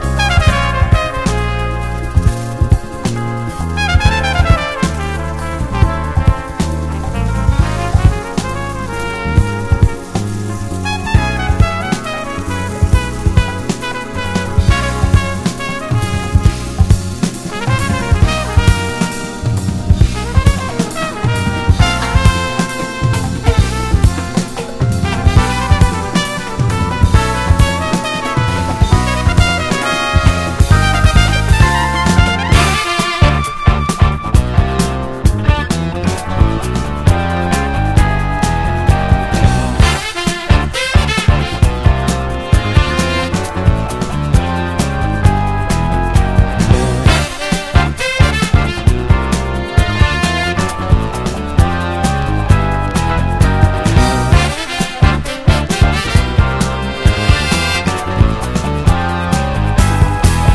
ROCK / 70'S
荒削りながらもパワフルに突き進むギター・サウンド＆オルガンが◎な
繊細で美しいメロディーが鳥肌モノの